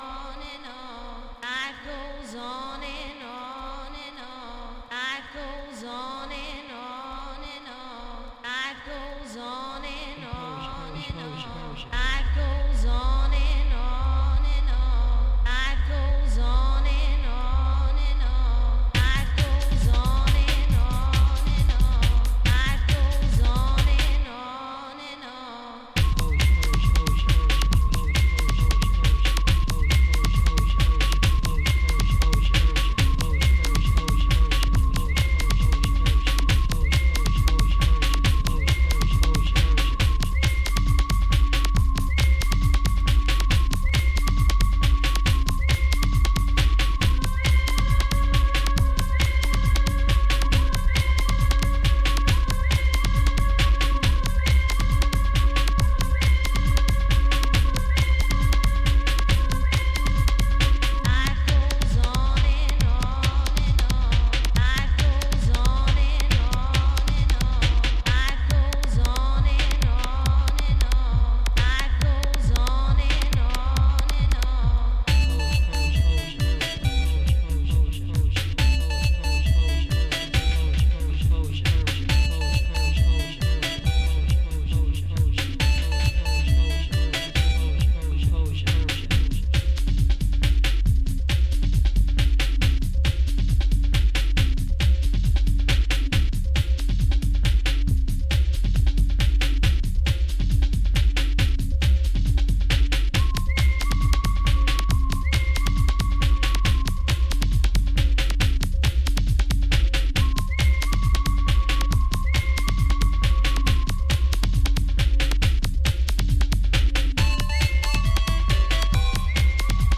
イカツイ低音が太々しく存在を主張したスーパーヘヴィ級グルーヴ
ブレイクビート・ハードコアの萌芽として確認出来る